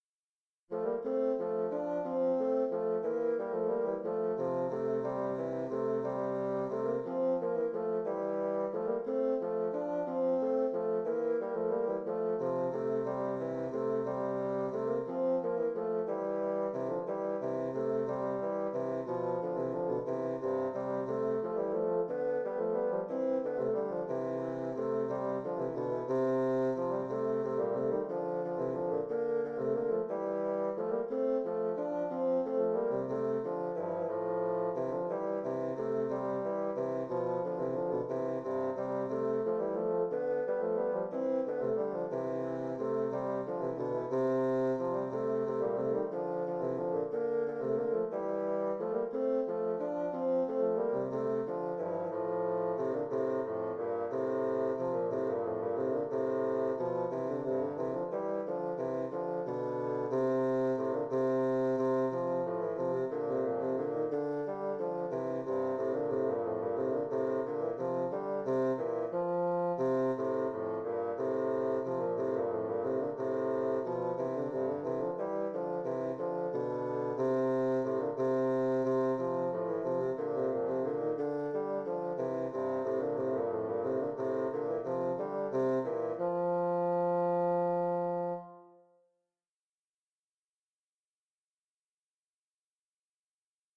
Intermediate bassoon duet